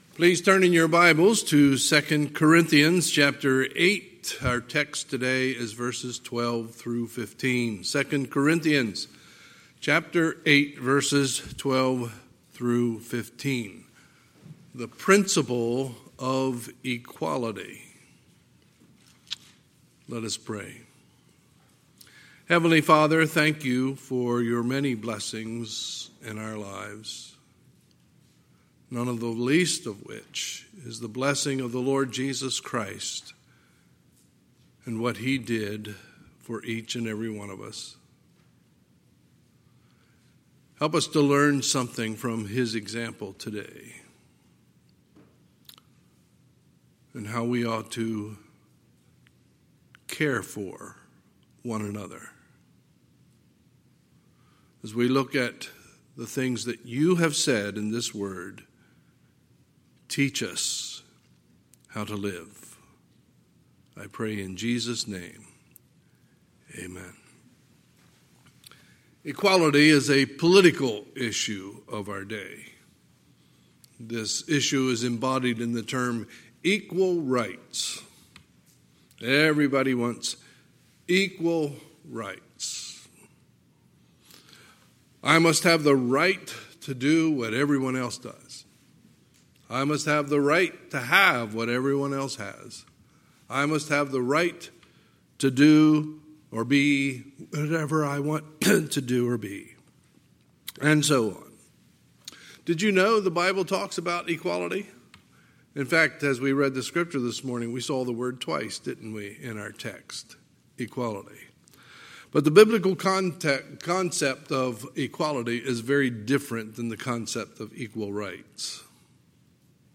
Sunday, August 23, 2020 – Sunday Morning Service